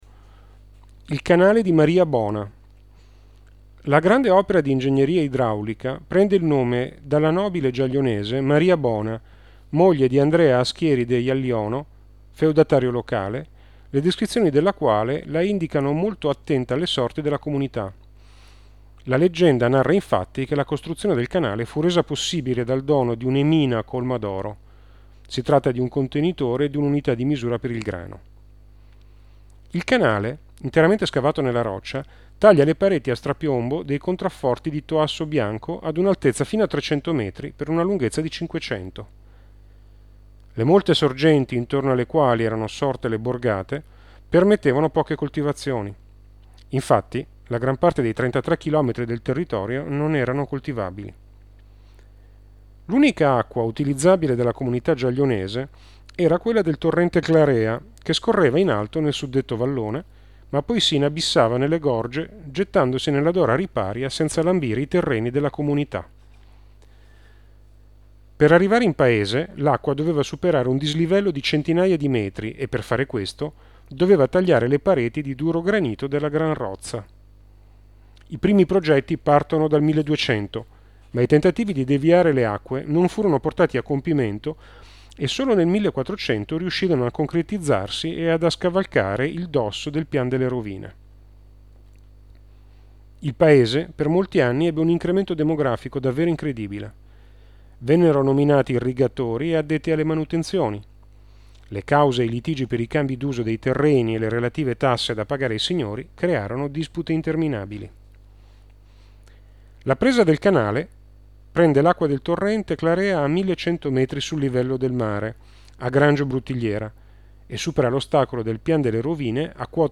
download di audioguida - italiano